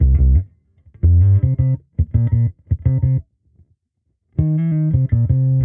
Index of /musicradar/sampled-funk-soul-samples/85bpm/Bass
SSF_PBassProc1_85C.wav